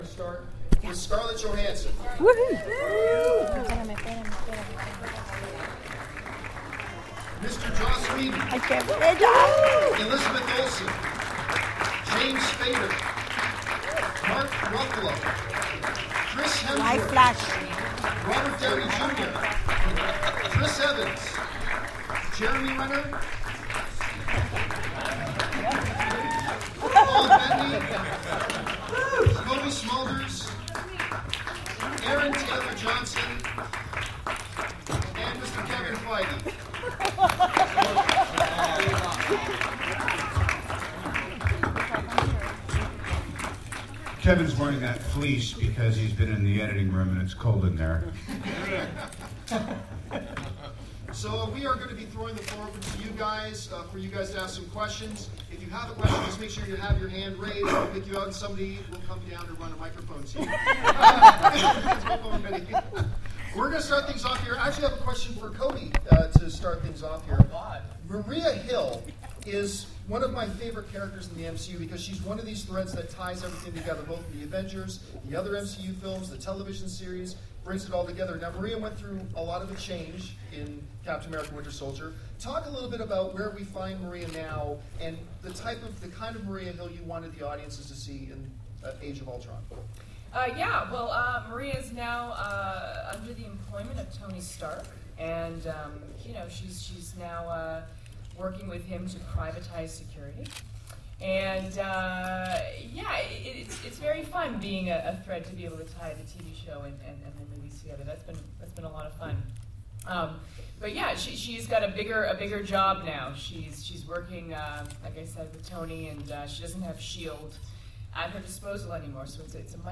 I got a chance to sit in at a press conference with ALL the major stars and director of the film.
4.11.15-Avengers-Press-Conference.mp3